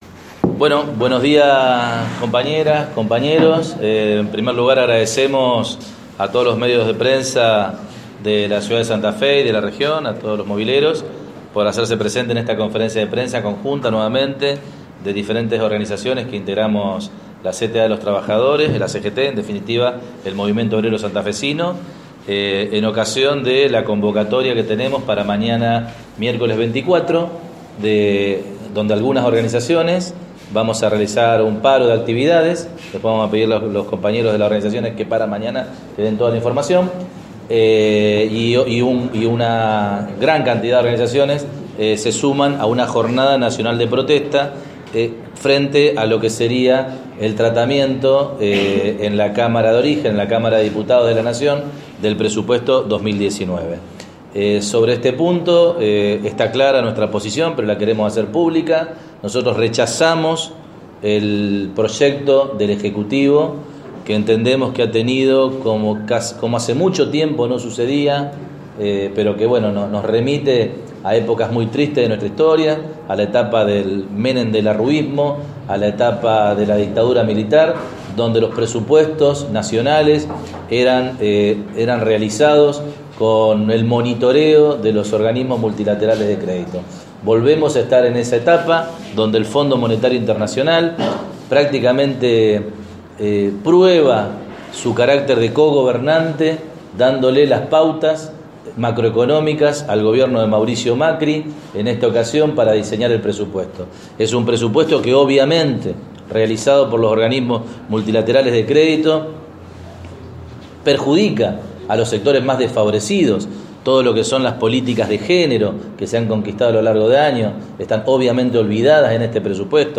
Conf. Prensa Jornada de Lucha 24/10/2018